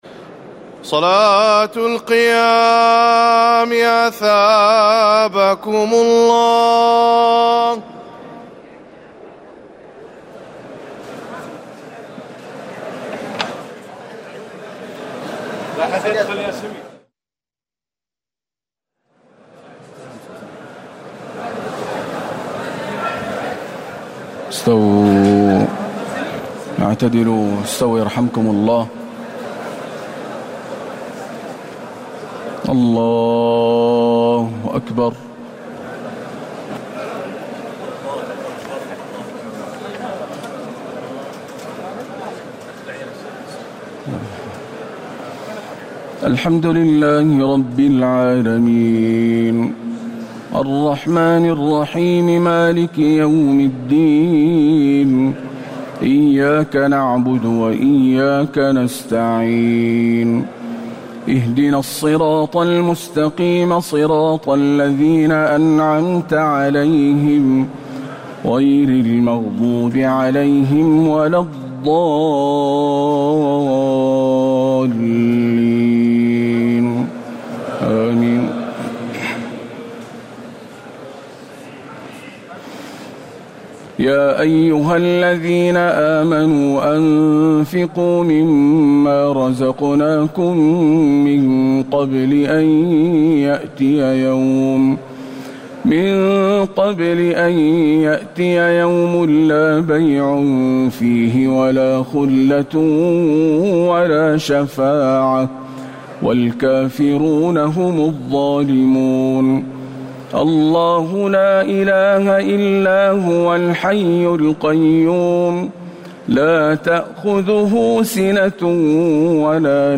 تهجد ليلة 23 رمضان 1439هـ من سورة البقرة (253-263) Tahajjud 23 st night Ramadan 1439H from Surah Al-Baqara > تراويح الحرم النبوي عام 1439 🕌 > التراويح - تلاوات الحرمين